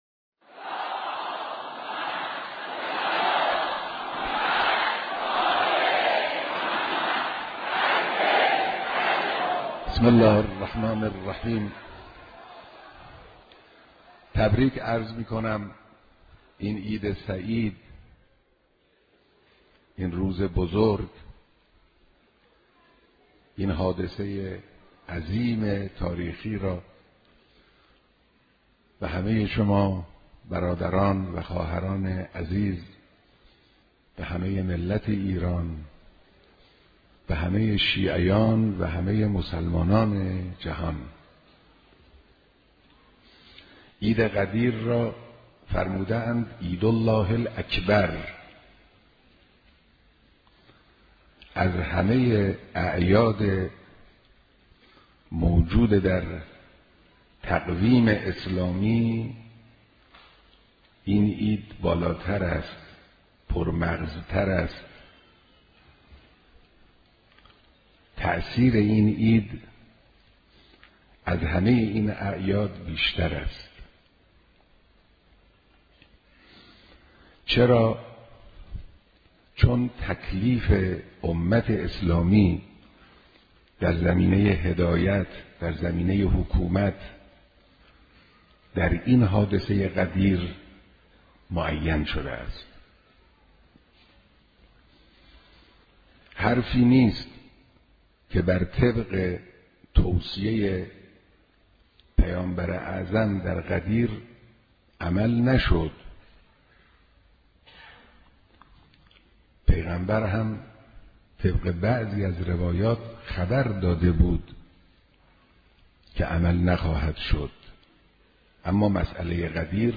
در این بخش، شما می‌توانید این سخنرانی ارزشمند را به‌صورت کامل بشنوید و با تأمل در بیانات روشنگرانه رهبر معظم انقلاب، نگاهی ژرف‌تر به حقیقت غدیر و فلسفه‌ی امامت و ولایت داشته باشید.